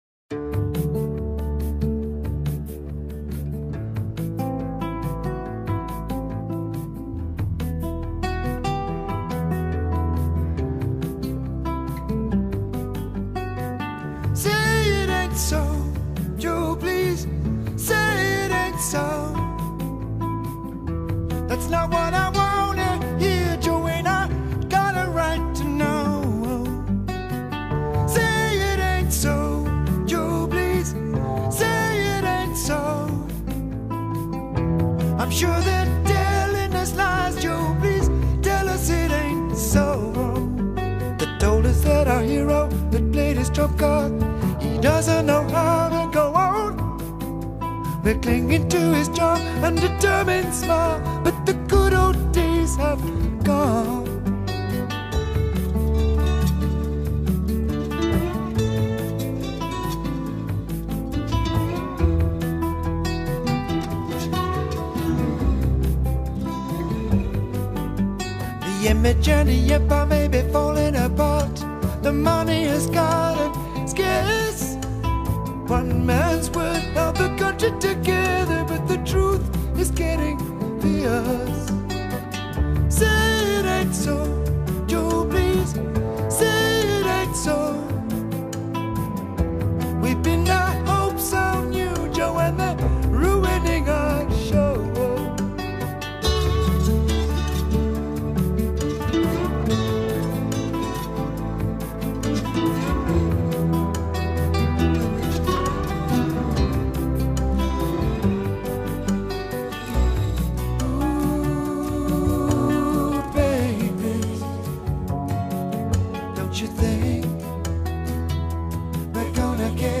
Chanson
Cette musique et sa voix résonnent comme un deuil